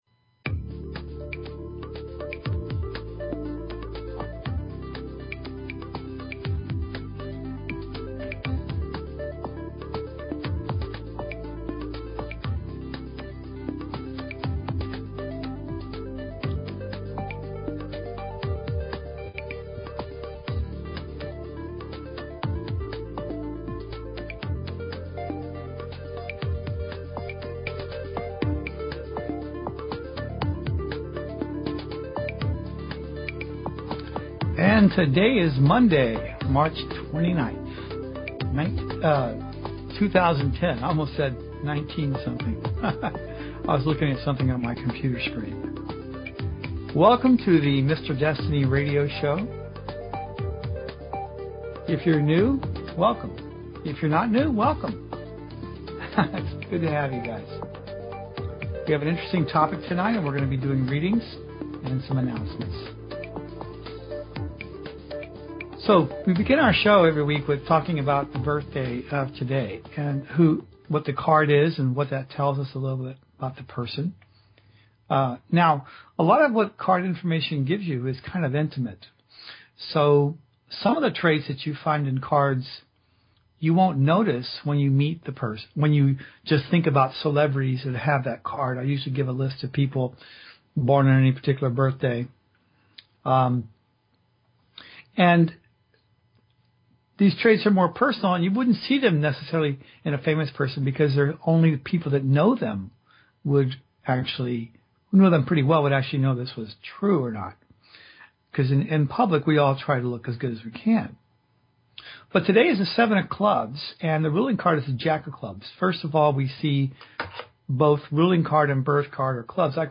Talk Show Episode
Predictions and analysis. Guest interview or topic discussion.